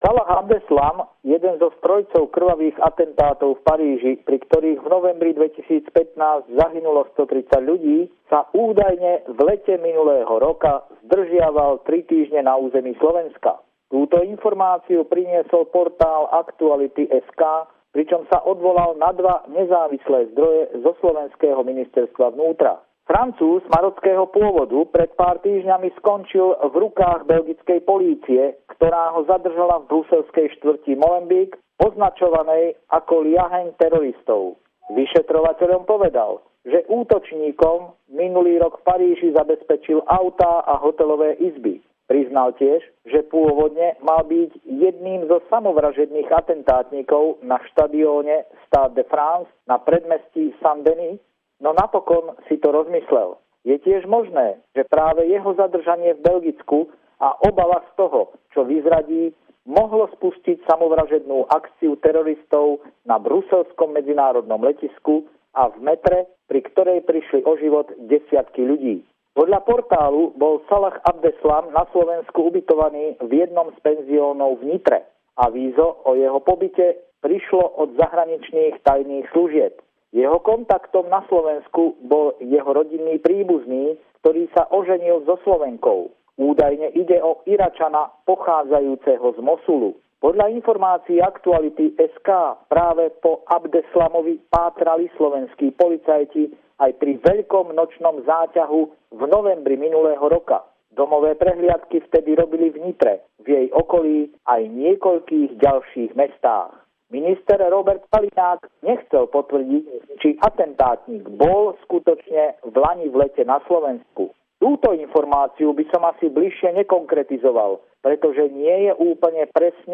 Pravidelný telefonát týždňa od nášho kolegu z Bratislavy